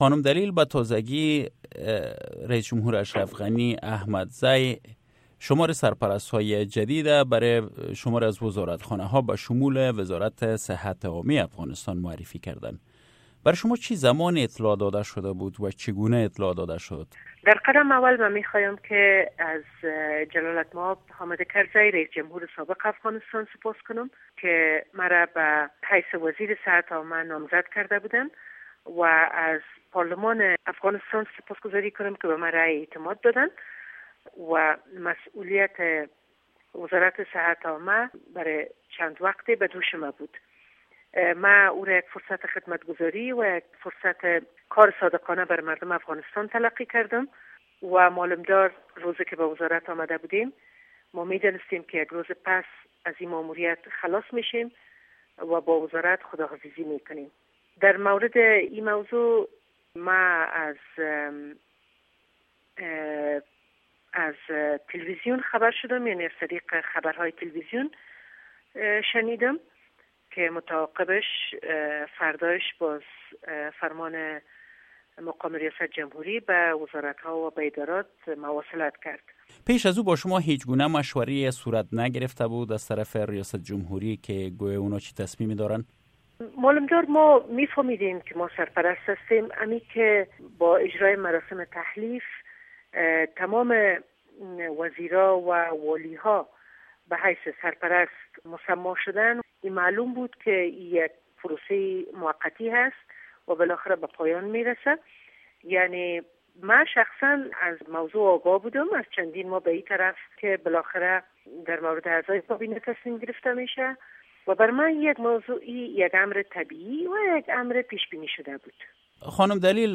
شرح کامل مصاحبه با داکتر ثریا دلیل را در اینجا بشنوید